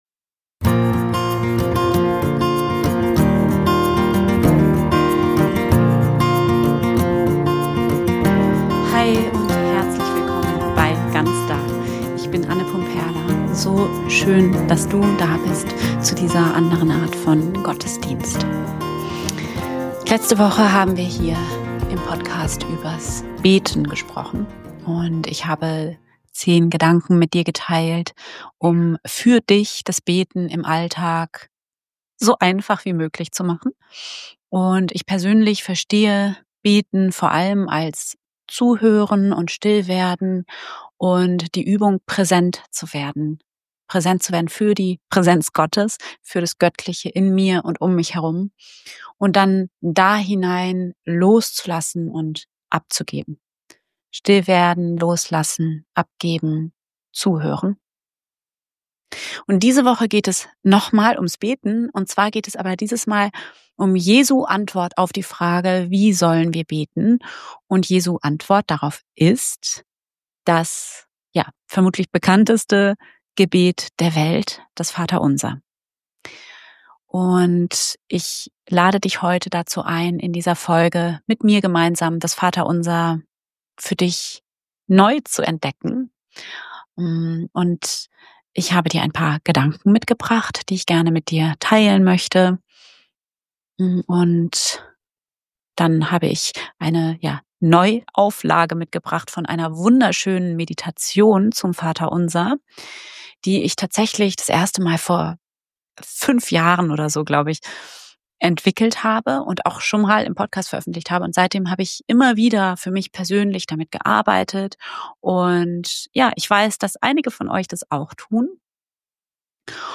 In dieser Folge nehmen wir das Vater Unser ganz neu in den Blick – mit einer persönlichen theologisch-spirituellen Deutung und einer geführten Meditation, die dir hilft, das Gebet nicht nur zu sprechen, sondern zu fühlen und für dich neu mit Bedeutung zu füllen.
Am Ende wartet eine Meditation auf dich, die dich in eine neue Tiefe führen kann – zurück zu deiner Quelle.